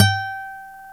Index of /90_sSampleCDs/Roland L-CDX-01/GTR_Nylon String/GTR_Nylon Chorus